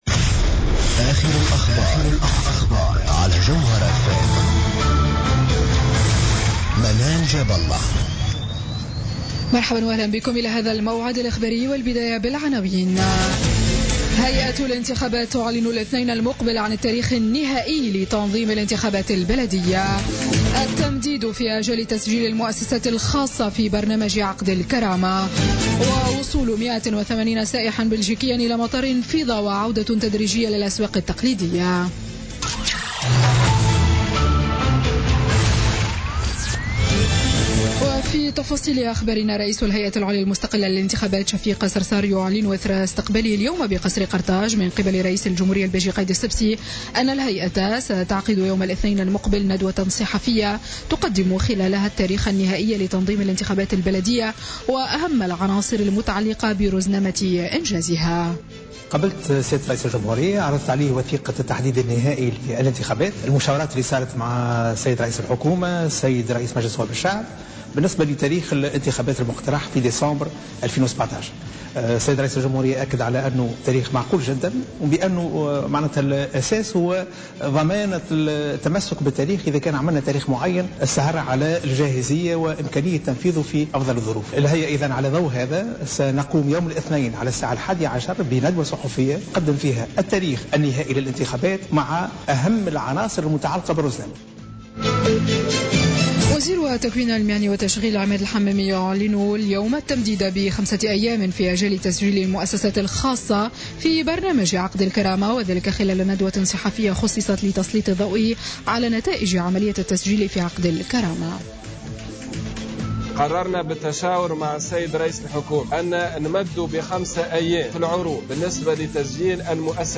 نشرة أخبار السابعة مساء ليوم الجمعة 31 مارس 2017